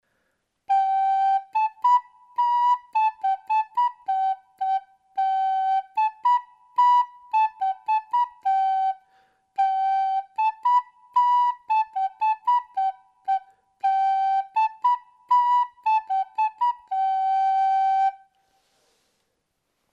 Outra melodía coas notas SOL, LA e SI.